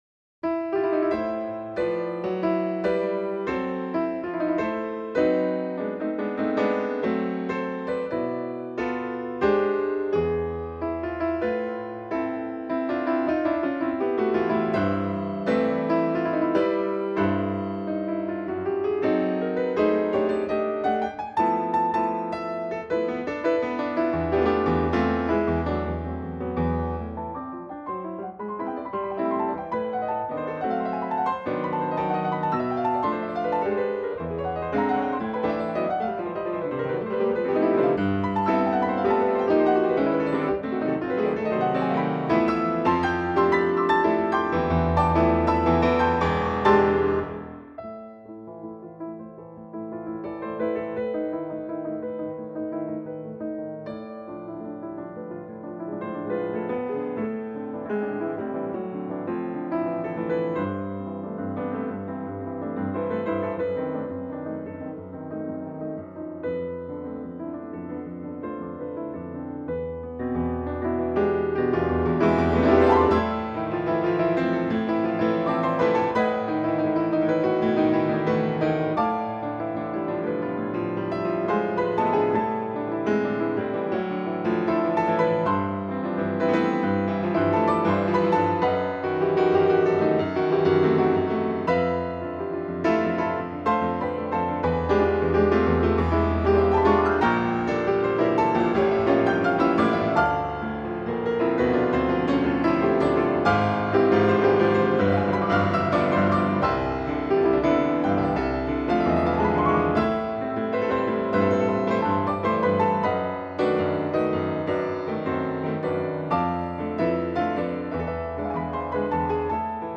piano solos